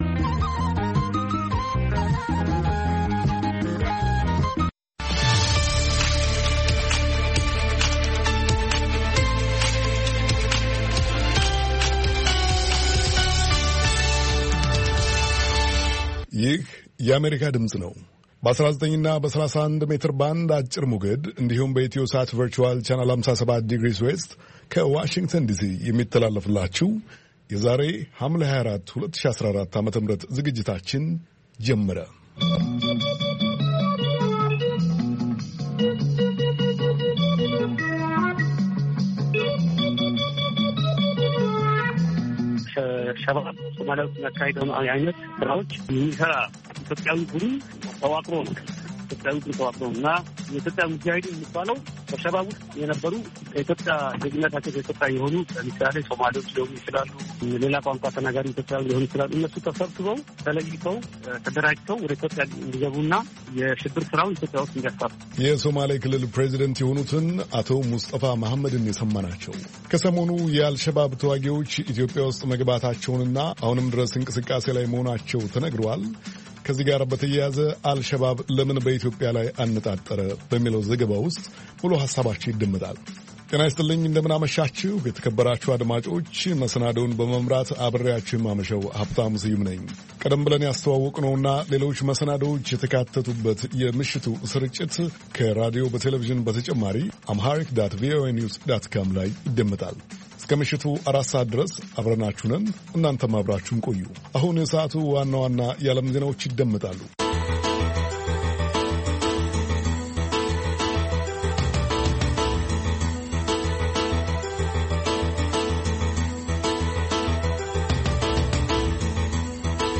ዕሁድ፡- ከምሽቱ ሦስት ሰዓት የአማርኛ ዜና